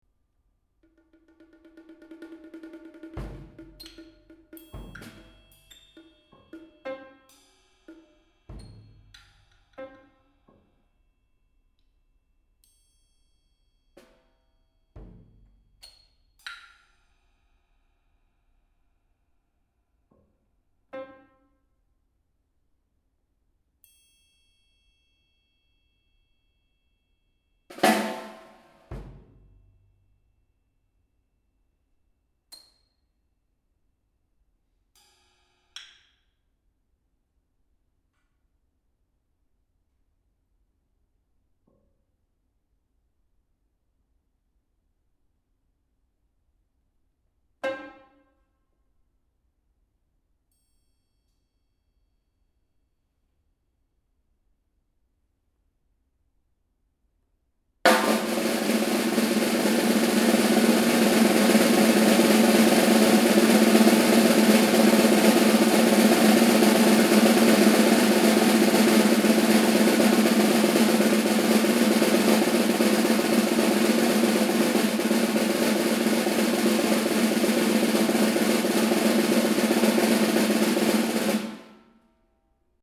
Rhythmic Étude
at the Konzerthaus Berlin on 7 November 2012.